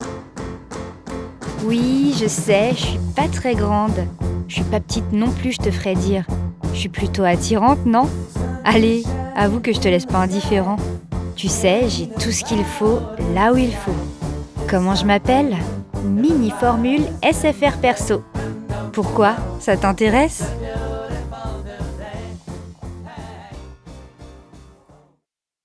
Voix off
Bandes-son